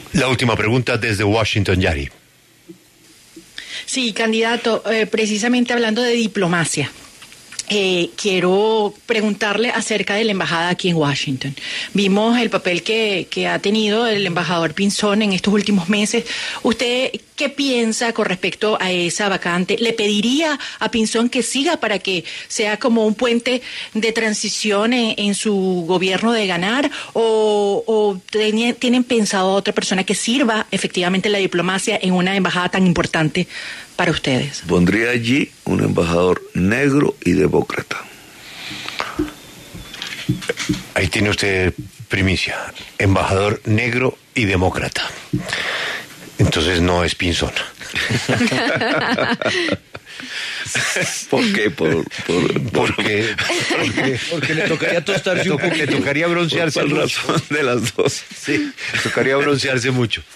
Gustavo Petro, precandidato presidencial, se refirió en La W sobre el embajador de Colombia que tendría en Washington en caso de quedar electo como mandatario.
Gustavo Petro, precandidato del Pacto Histórico, habla en La W